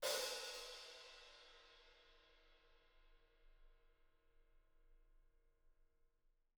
R_B Hi-Hat 09 - Room.wav